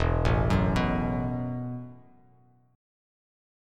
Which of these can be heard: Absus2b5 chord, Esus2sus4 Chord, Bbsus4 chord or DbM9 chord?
Esus2sus4 Chord